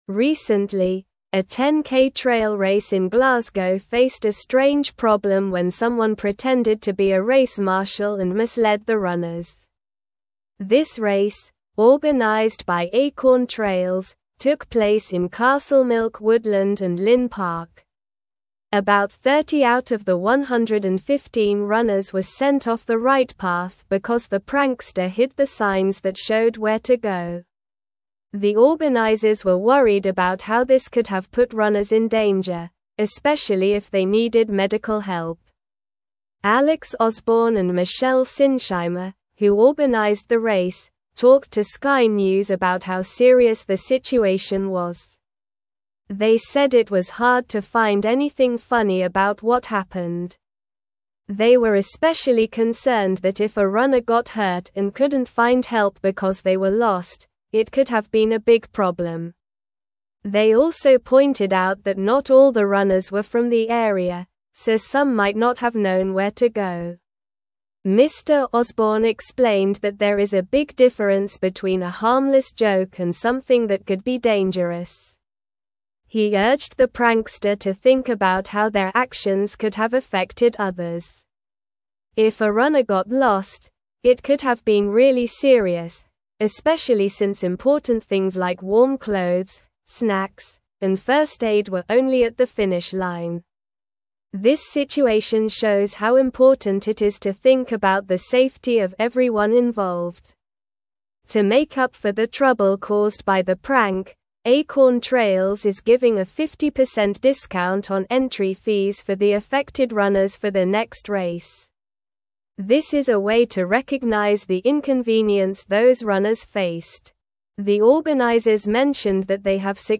Slow
English-Newsroom-Upper-Intermediate-SLOW-Reading-Prankster-Disrupts-Glasgow-10k-Trail-Race-Direction.mp3